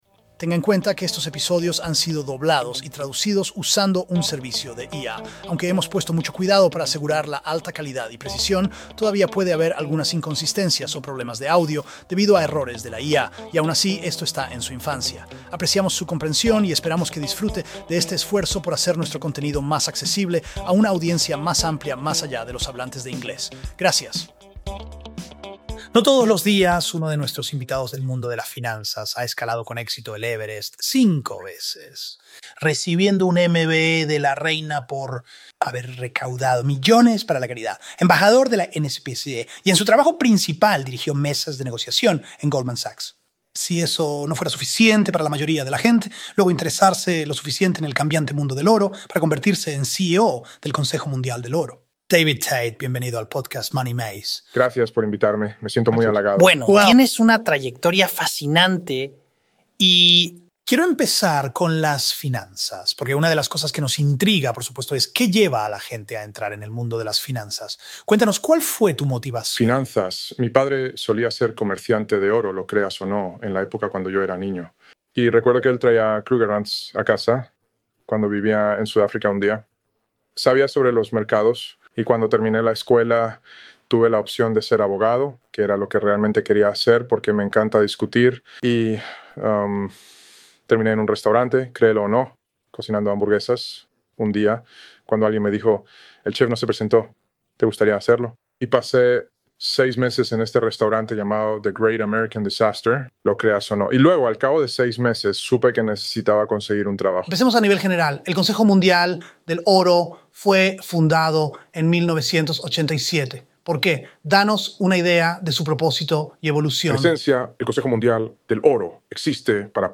En esta fascinante conversación